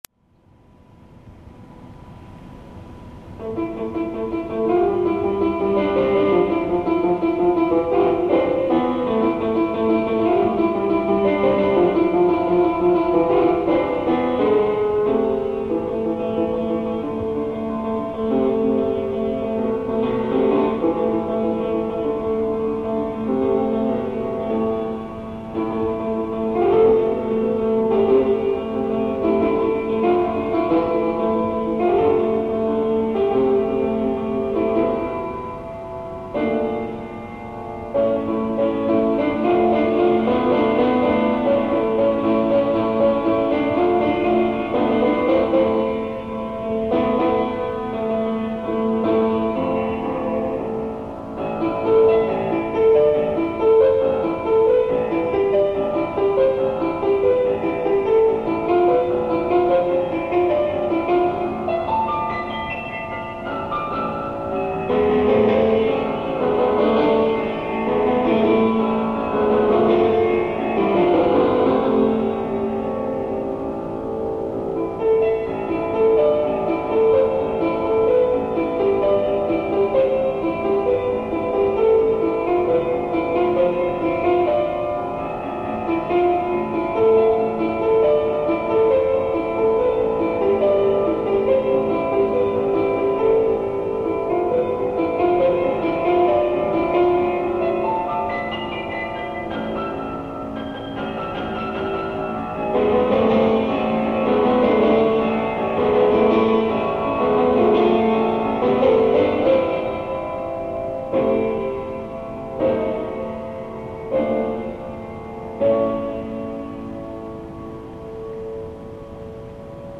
Live Piano Compositions
These exciting recordings are taken off of a low quality tape I recorded with a simple recorder at age 15 to archive some of my very first compositions.